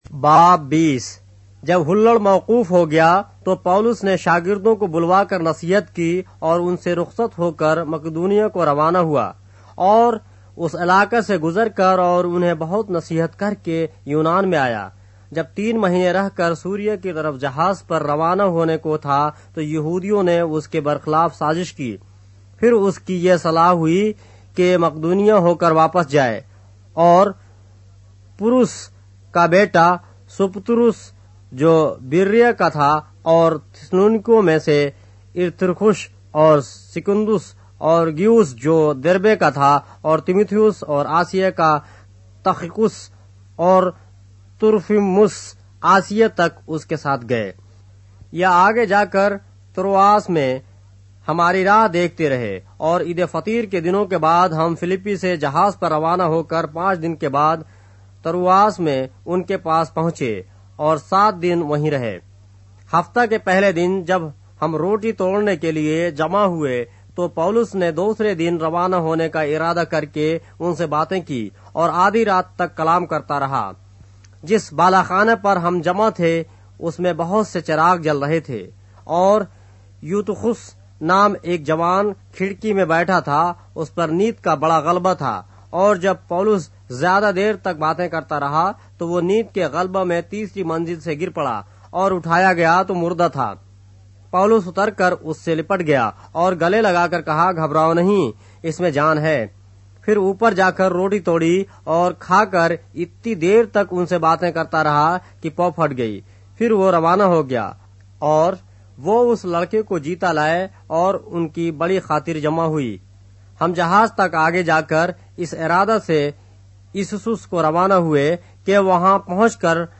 اردو بائبل کے باب - آڈیو روایت کے ساتھ - Acts, chapter 20 of the Holy Bible in Urdu